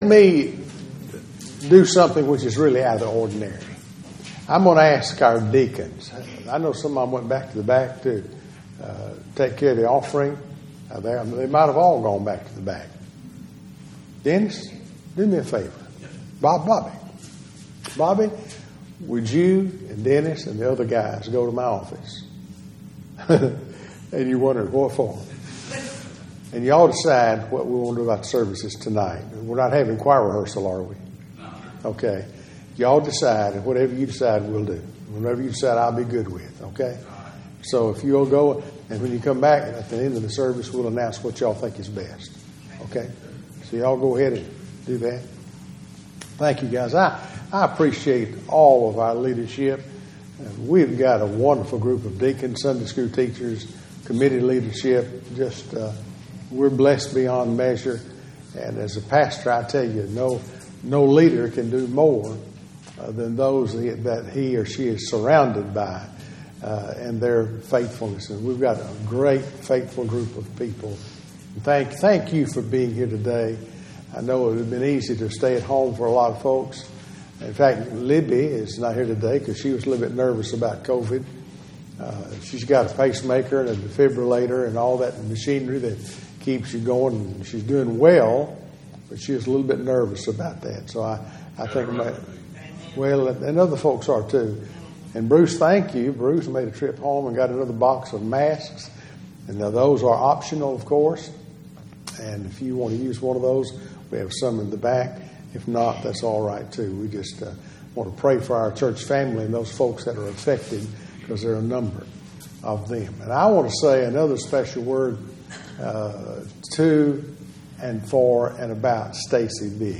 Sermons | Calvary Baptist Church